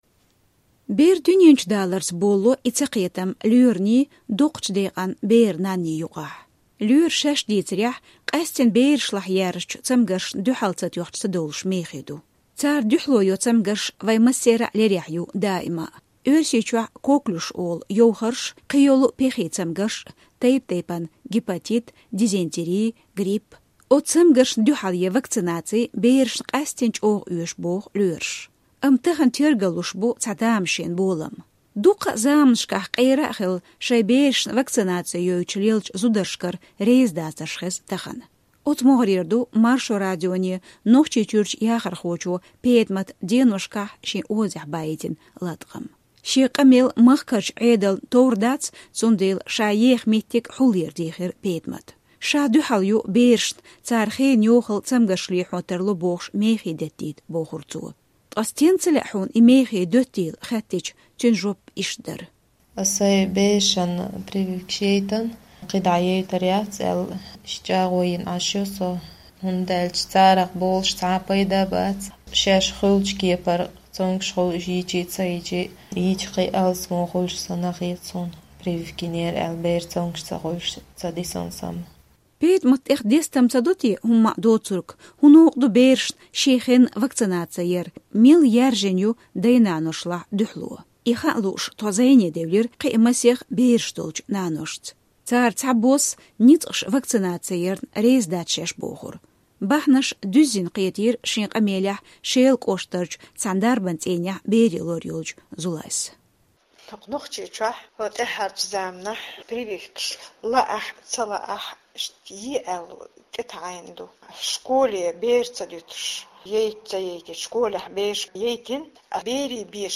И хаа лууш тхо зIене девлира кхин а масех бераш долчу наношца.